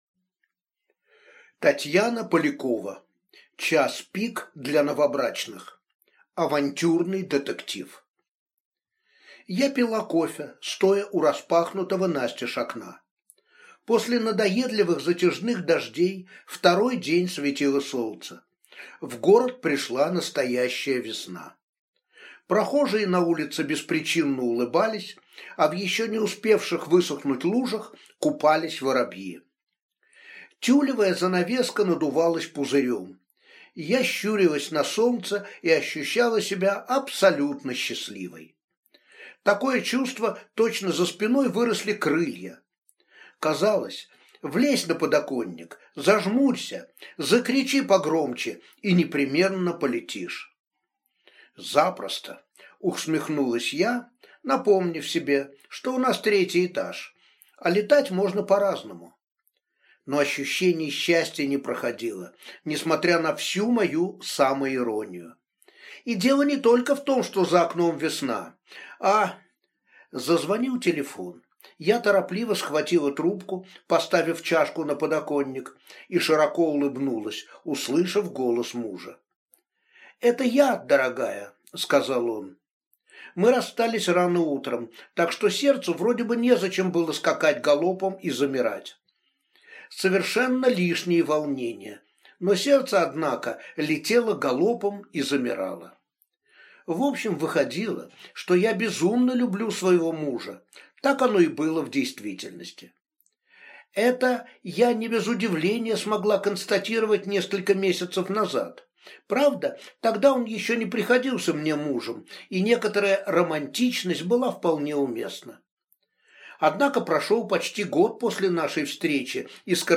Аудиокнига Час пик для новобрачных | Библиотека аудиокниг